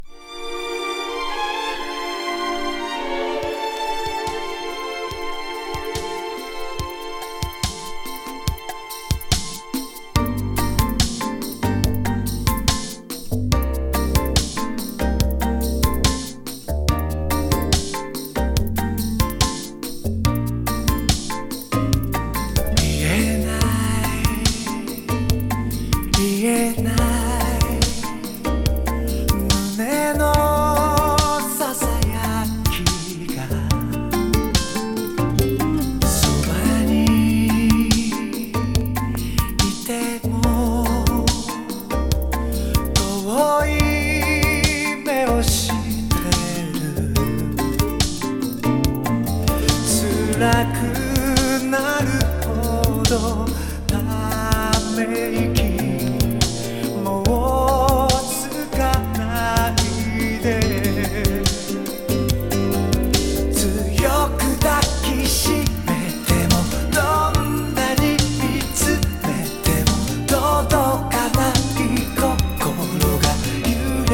ドラムマシーンと、アンニュイなアレンジが最高にマッチした、和レアリックバラード！